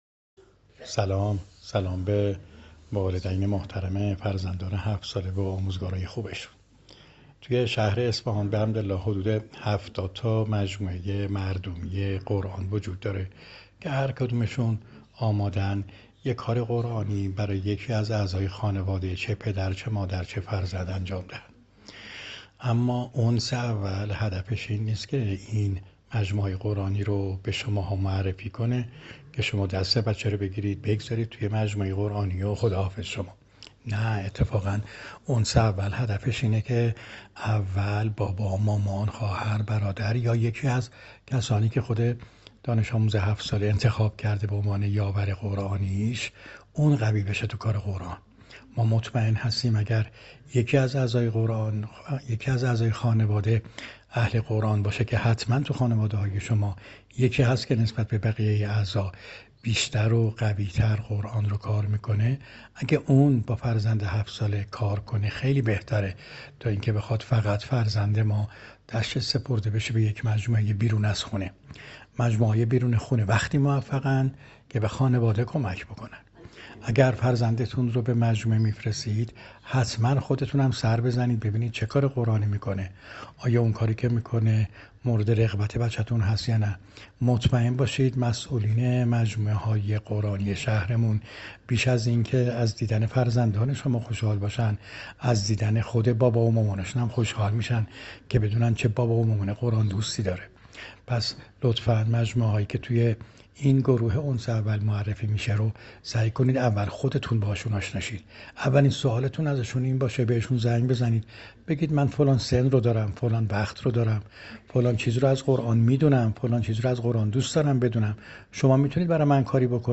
پاسخ صوتی